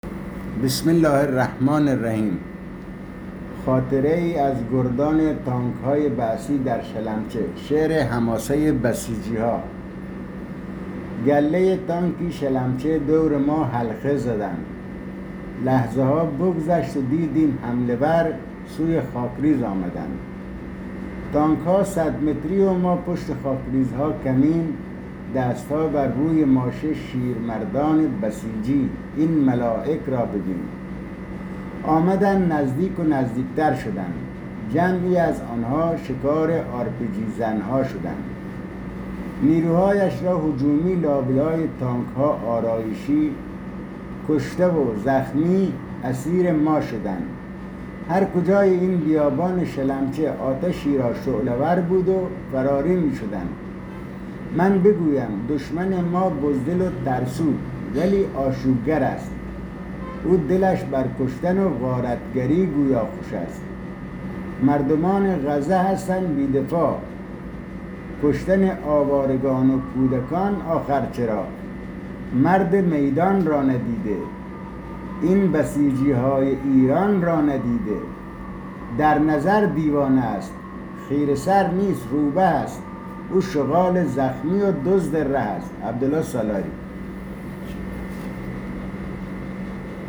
صوت شعر با صدای شاعر و جانباز سرافراز